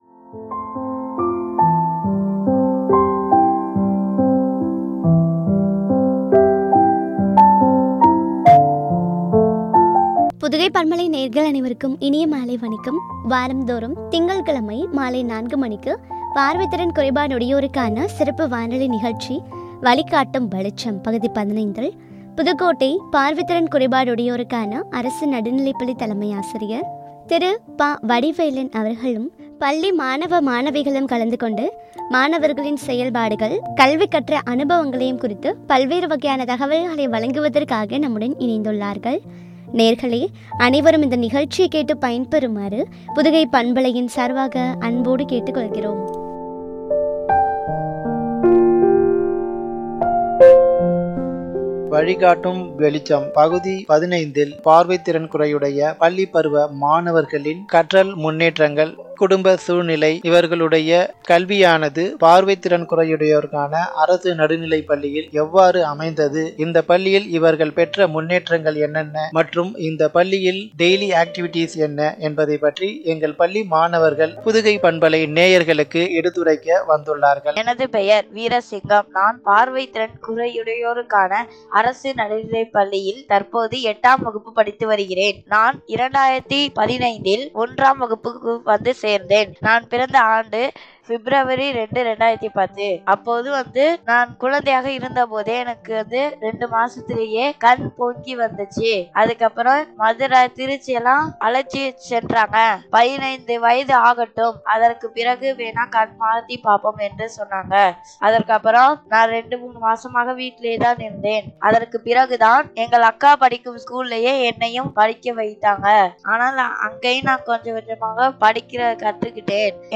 பார்வை திறன் குறையுடையோருக்கான சிறப்பு வானொலி நிகழ்ச்சி
” மாணாக்கர்கள் செயல்பாடுகள் குறித்து வழங்கிய உரையாடல்.